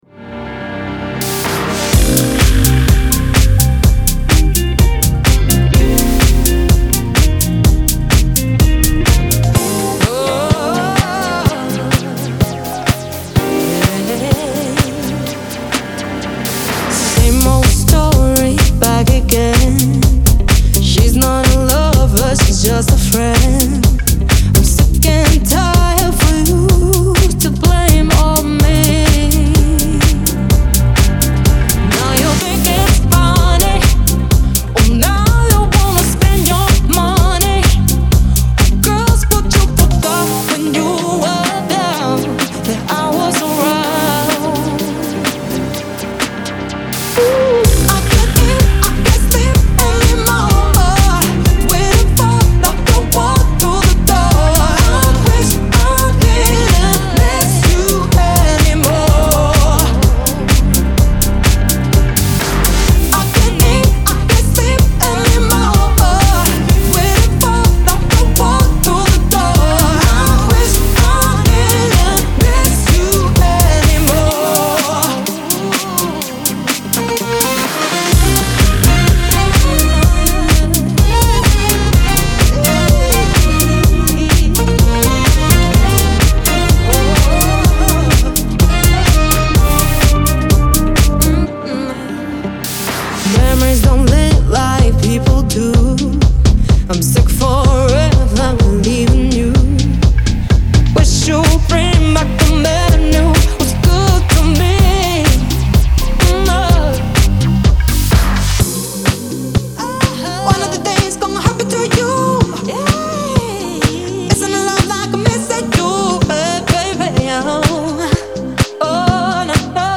диско
pop , dance
дуэт